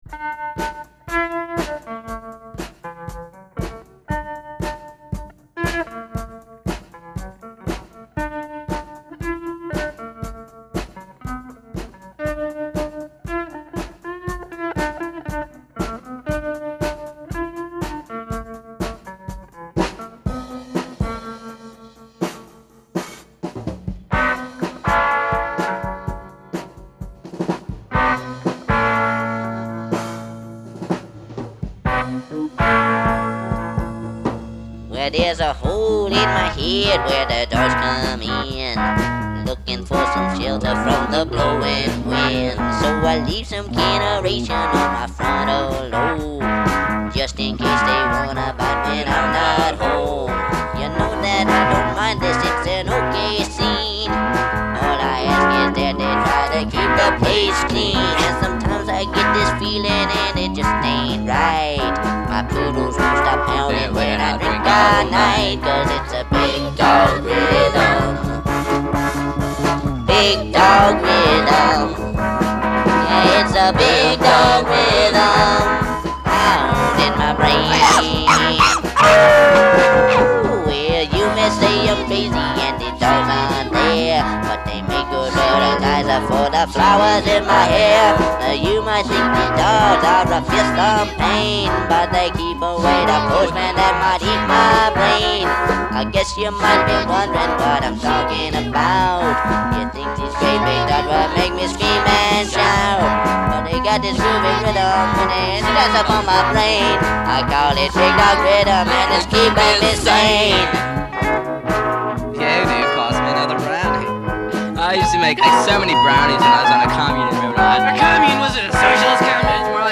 We got our little sisters to sing the shooby-doo-wop backing vocals. I think it’s another song about mental illness, sorta.